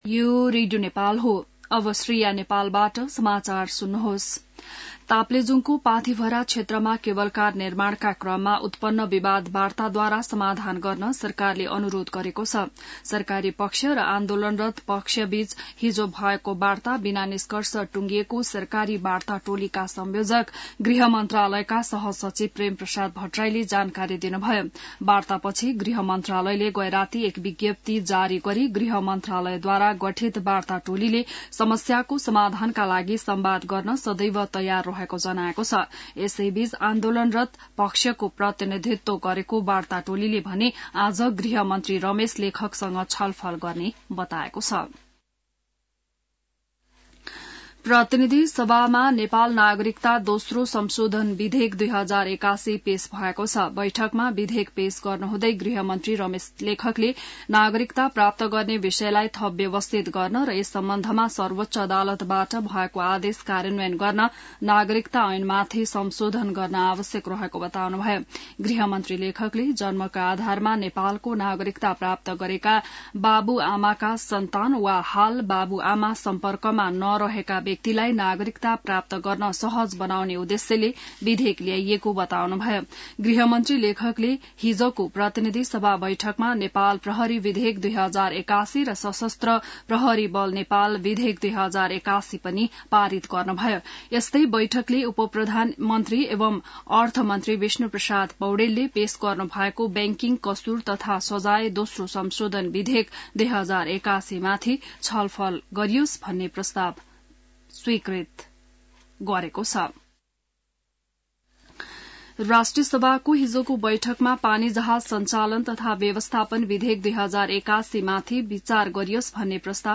बिहान ६ बजेको नेपाली समाचार : २९ माघ , २०८१